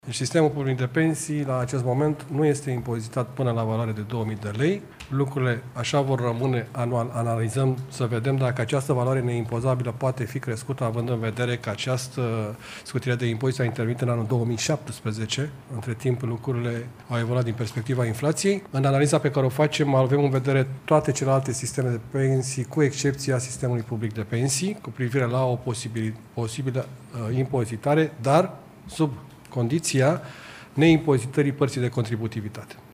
Executivul analizează ca și anumite pensii, peste valoarea de 2 mii de lei lunar, să fie scutite de la impozitare. Ministrul Finanțelor, Adrian Câciu spune că deocamdată se face o analiză împreună cu Ministerul Muncii pentru a vedea până la ce prag pensiile ar putea să nu fie scutite de impozit.